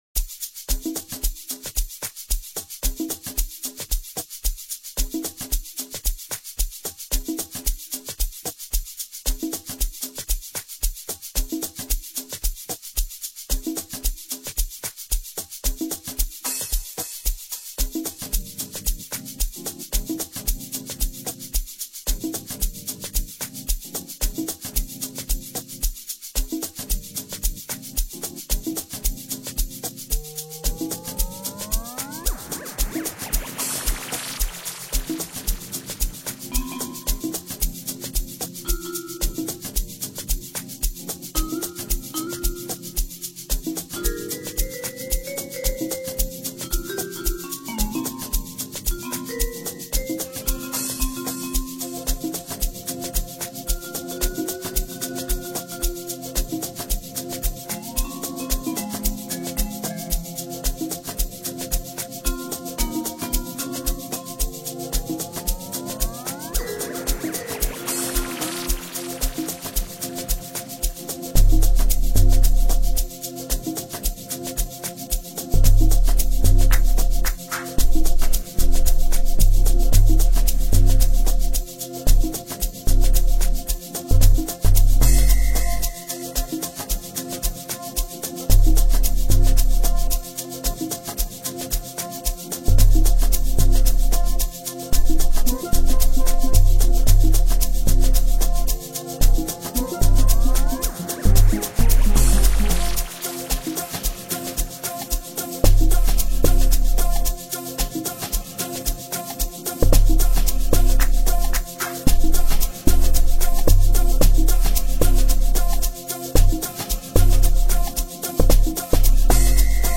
banging tracks
melodic offerings
an instrumental package